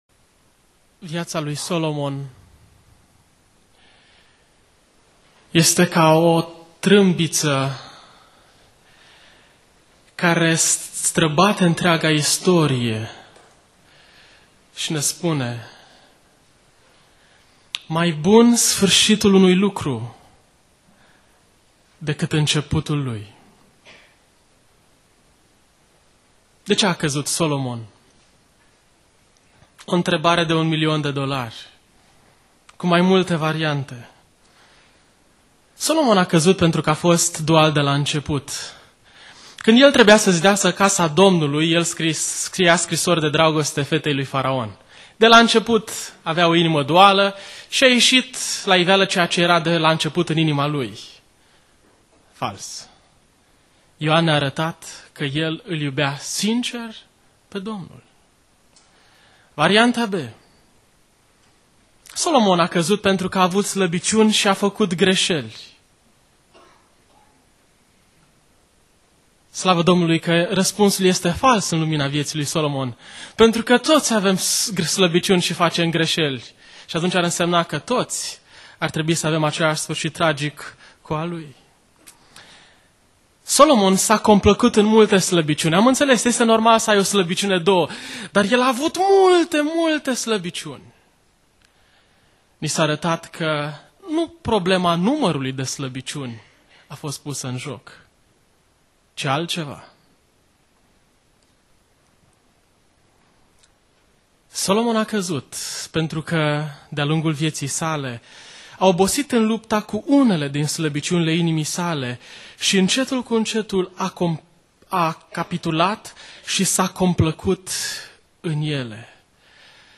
Predica Aplicatie - 1 Imparati Cap 3-4